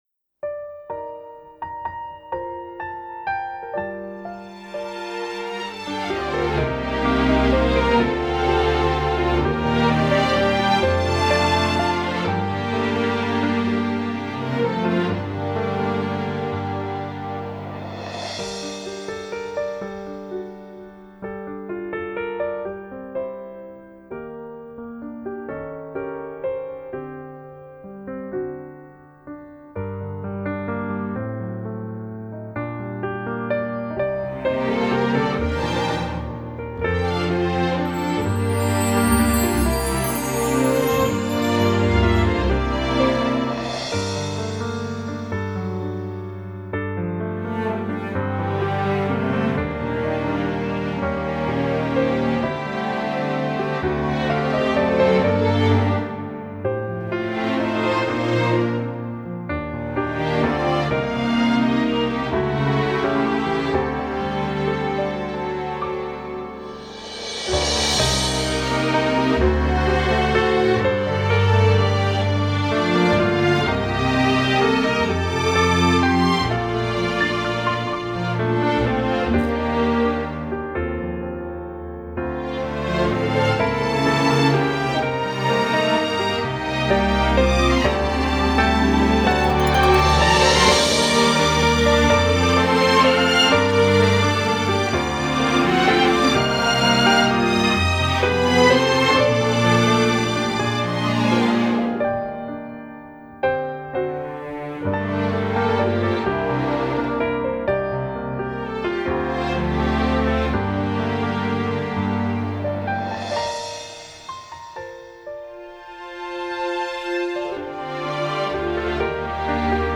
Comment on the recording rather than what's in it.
I’m going to use the instrumental version to sing along to.